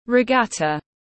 Môn đua thuyền tiếng anh gọi là regatta, phiên âm tiếng anh đọc là /rɪˈɡɑːtə/ .
Regatta /rɪˈɡɑːtə/
Regatta.mp3